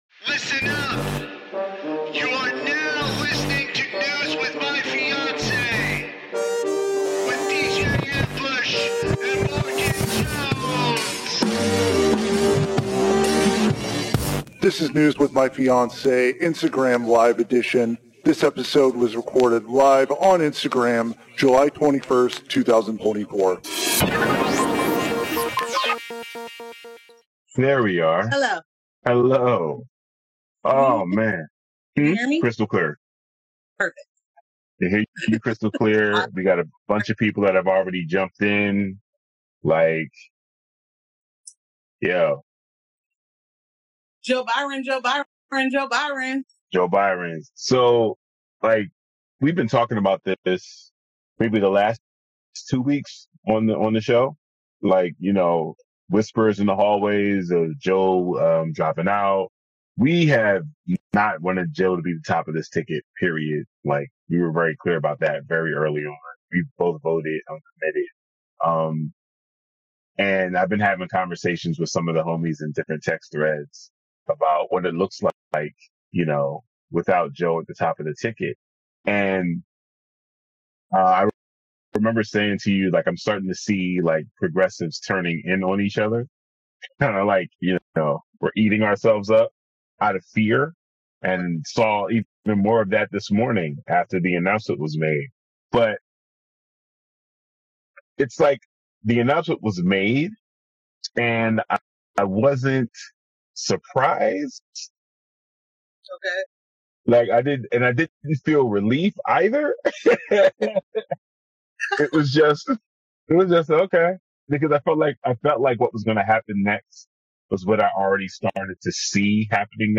Recorded on INSTAGRAM LIVE.